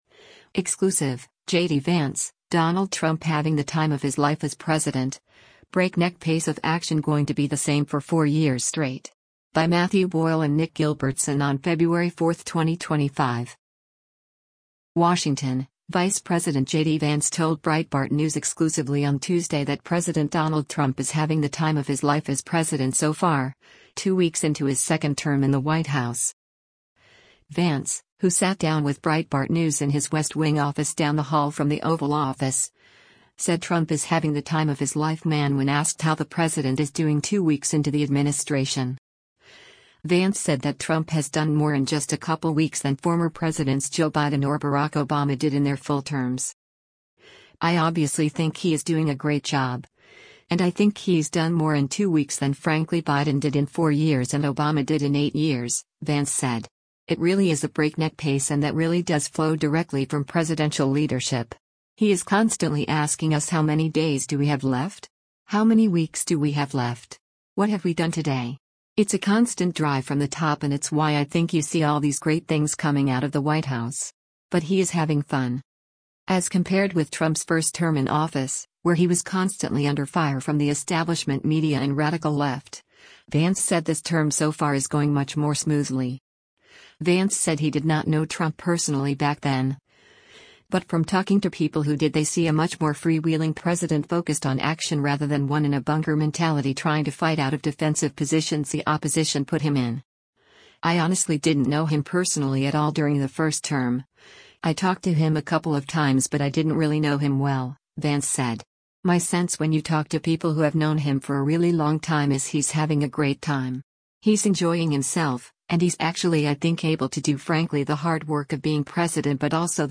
Vance, who sat down with Breitbart News in his West Wing office down the hall from the Oval Office, said Trump is “having the time of his life man” when asked how the president is doing two weeks into the administration.
More from Vance’s first exclusive interview with Breitbart News as vice president from inside the West Wing at the White House is forthcoming soon.